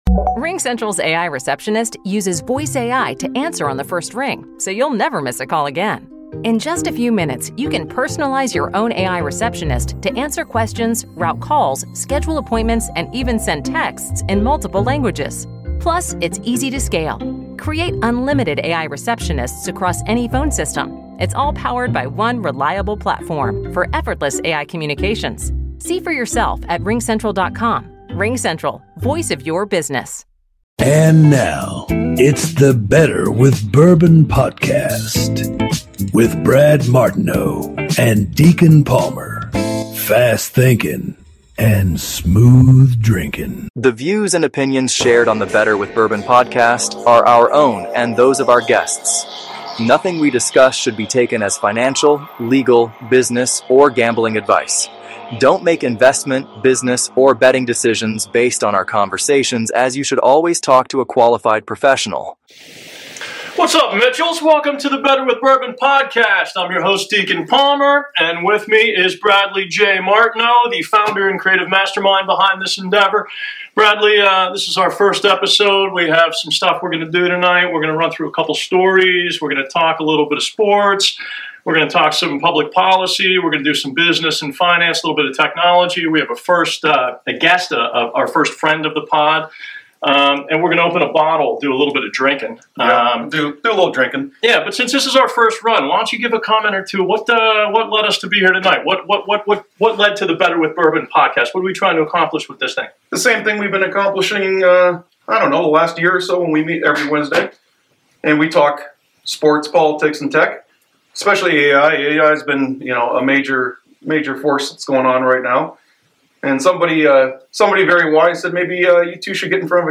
The voice overs are original AI generated voices. And the 'Better with Bourbon' theme music is an original instrumental song that was created using AI.